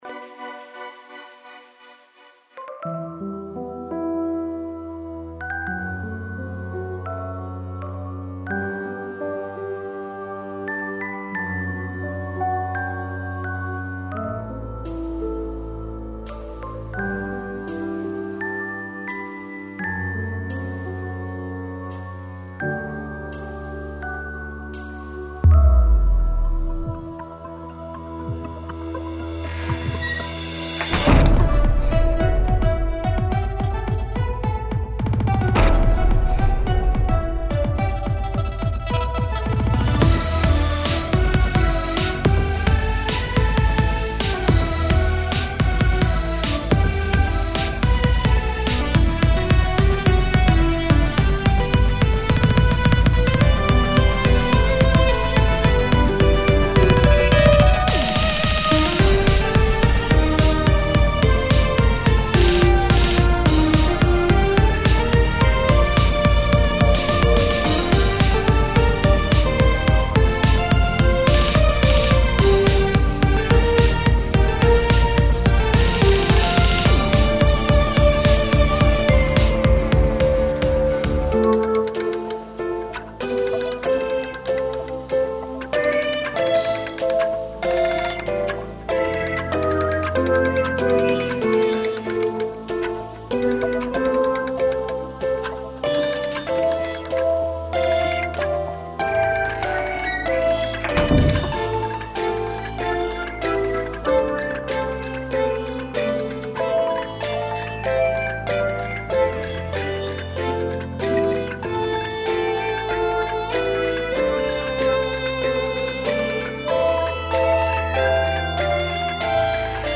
戦闘曲